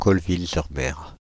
Colleville-sur-Mer (French pronunciation: [kɔlvil syʁ mɛʁ]
Fr-Paris--Colleville-sur-Mer.ogg.mp3